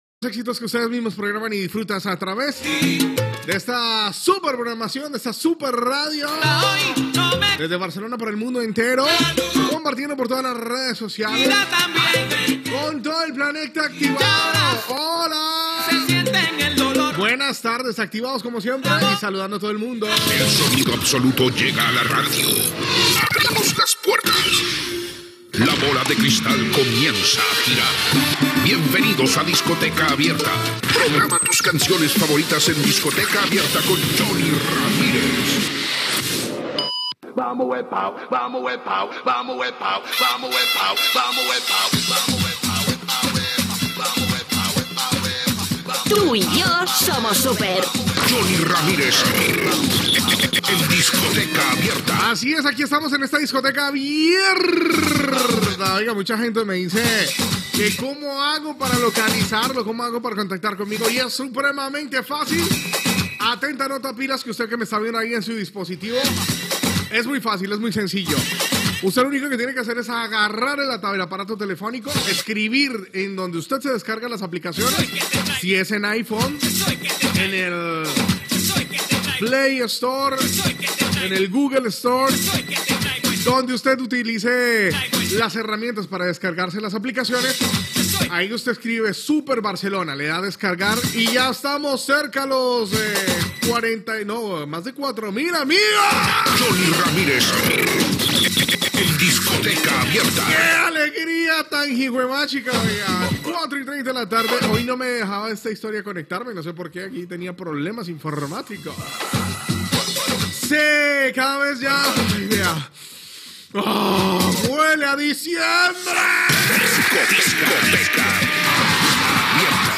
Salutació, careta del programa, com descarregar l'aplicació de la ràdio, comentari dels menjars de Nadal, previsió per a l'any 2020, telèfon de participació, publicitat Gènere radiofònic Musical